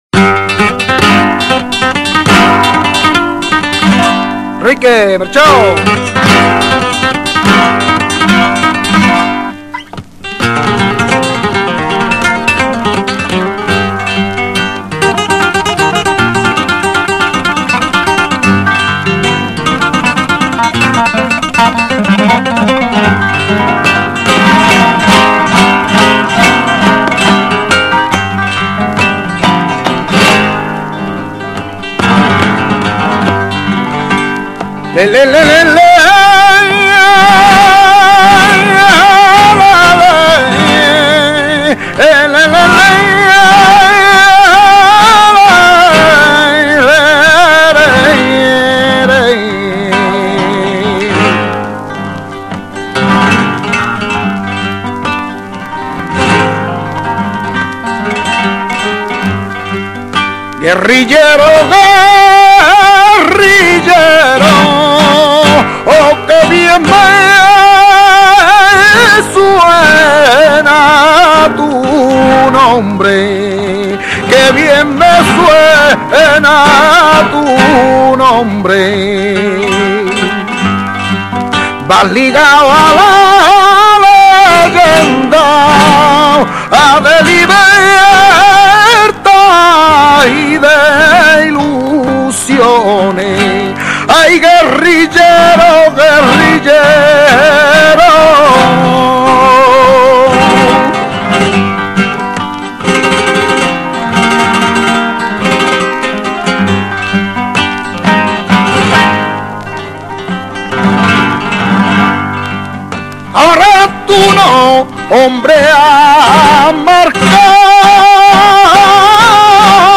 Cante:
Guitarras: